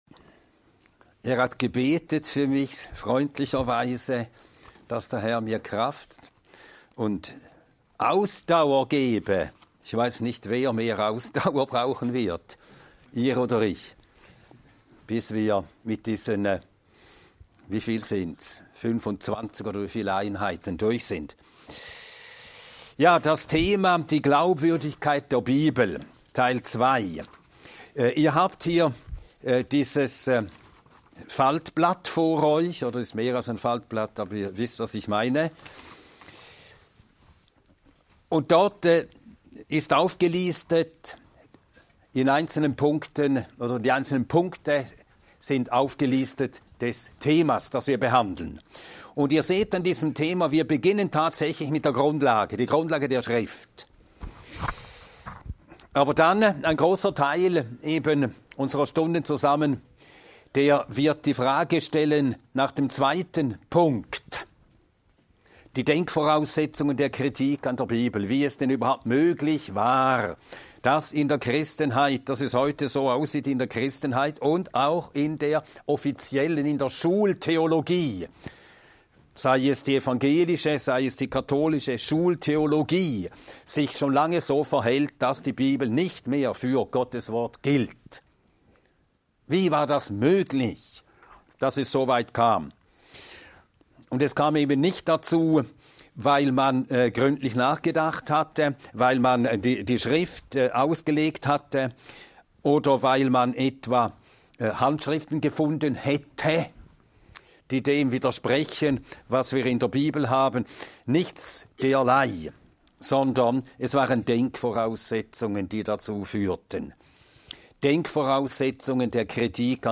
Dieser Vortrag beleuchtet, warum die Bibel über Jahrhunderte hinweg als irrtumsloses Wort Gottes verstanden wurde – und wie die Reformation dieses Verständnis erneuerte. Ausgehend von der biblischen Grundlage und den Ereignissen um Martin Luther wird gezeigt, wie das Schriftprinzip („sola scriptura“) entstand und welche Sprengkraft es für Kirche und Gesellschaft hatte.